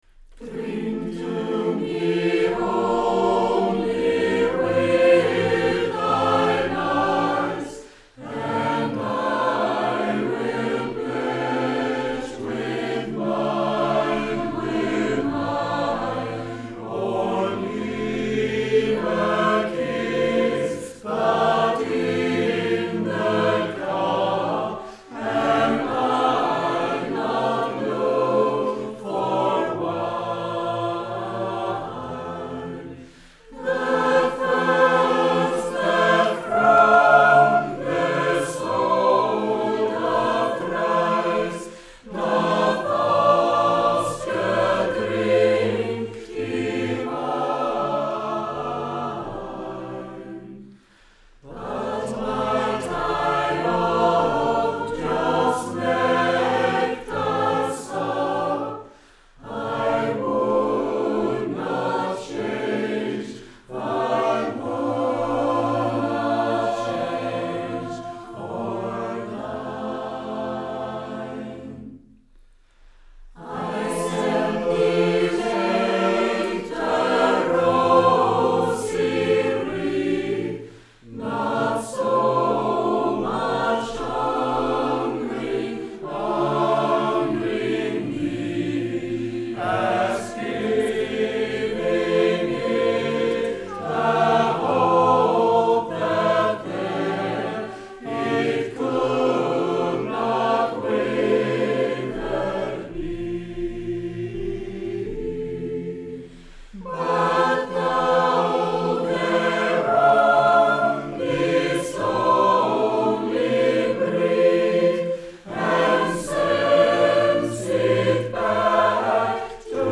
Inspelning Kvidinge 2013